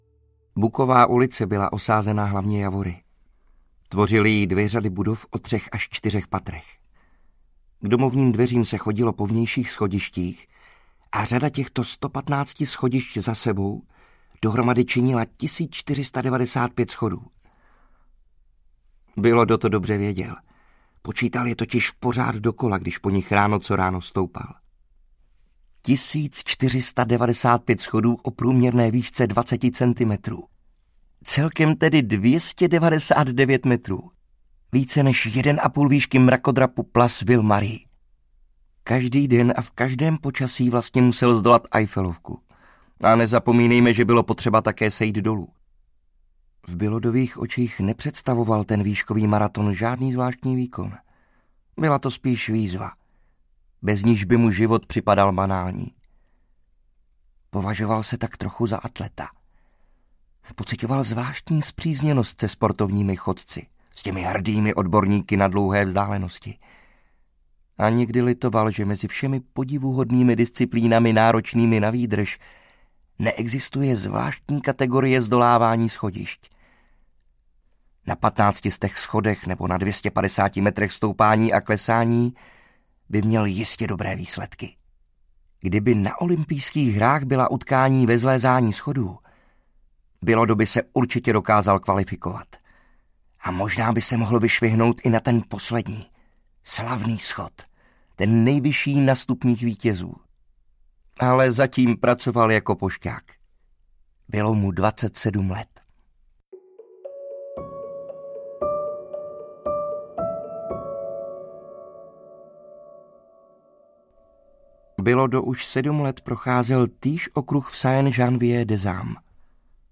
Podivuhodný život osamělého pošťáka audiokniha
Ukázka z knihy